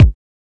Kick
Original creative-commons licensed sounds for DJ's and music producers, recorded with high quality studio microphones.
Kick One Shot A Key 15.wav
00s-boomy-kick-drum-single-hit-a-key-19-Zpd.wav